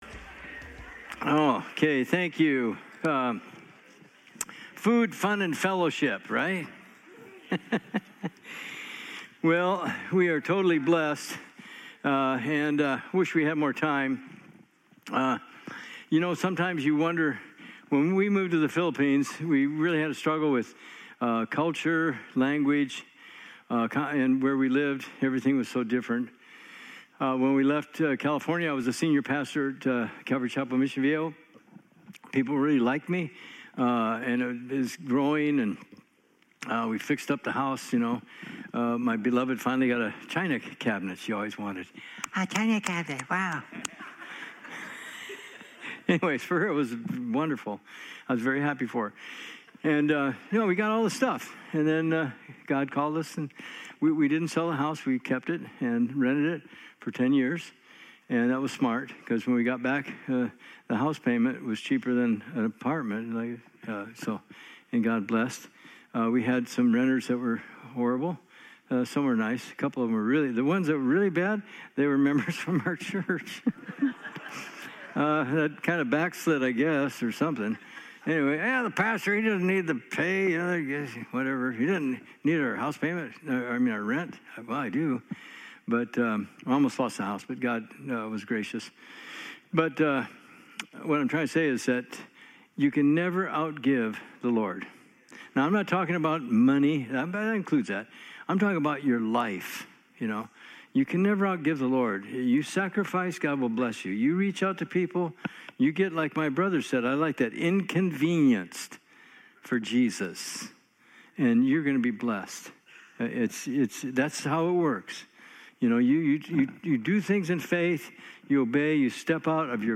Inductive Bible Study
sermons